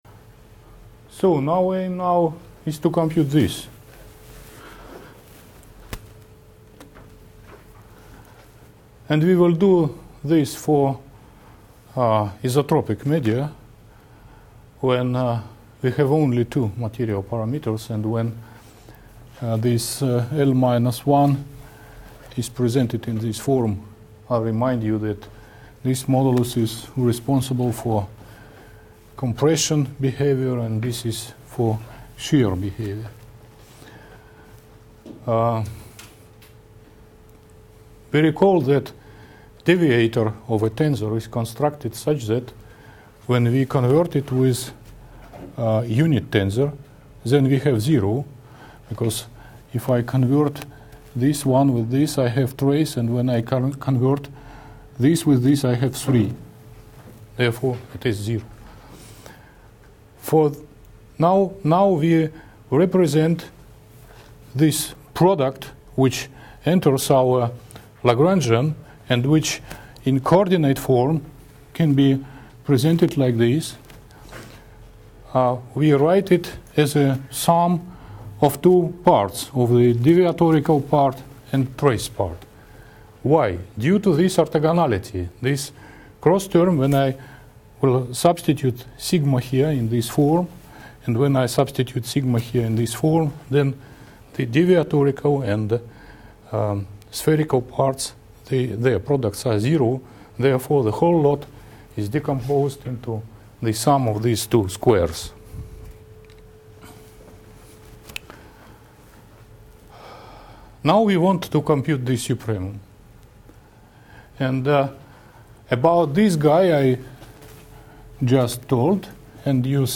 lecture series on mathematical theory of plasticity 3.3.2011, part 2/2